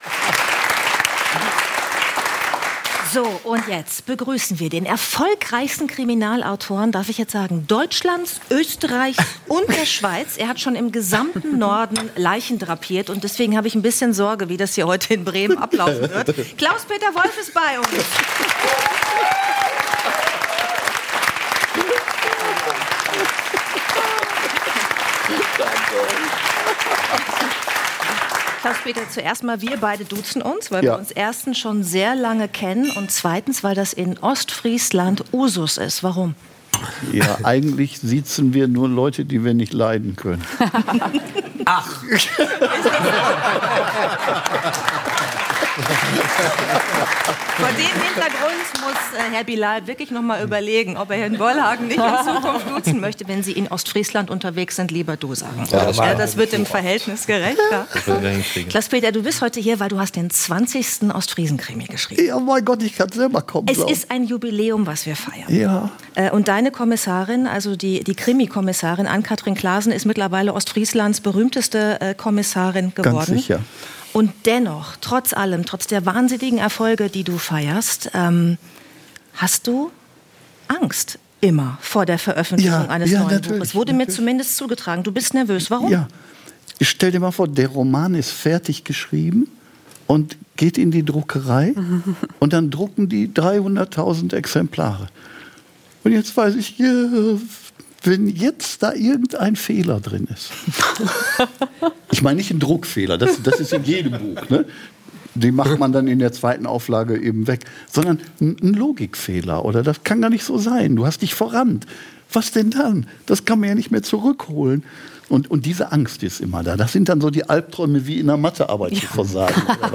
Der Talk